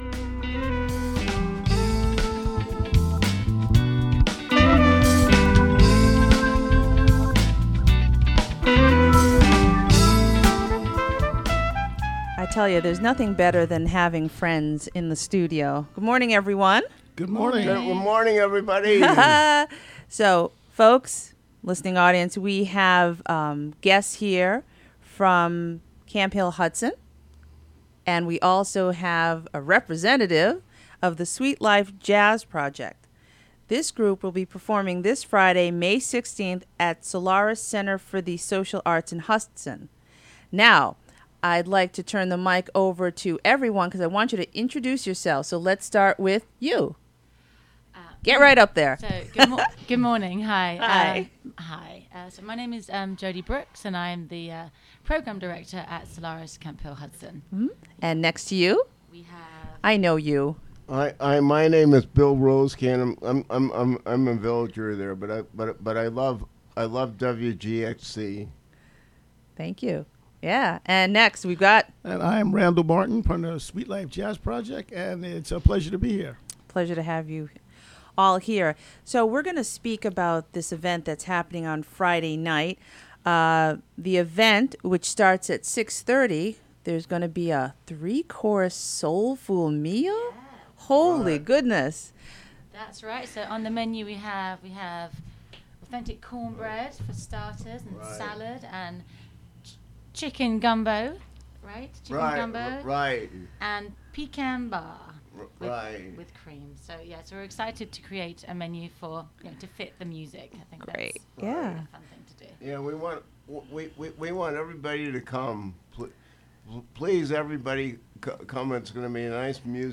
Interview with members of The Sweet Life Jazz Project. They will perform on Fri., May 16, at the Solaris Center for the Social Arts in Hudson, an event that includes a three-course soul food meal served by Camphill Hudson and friends.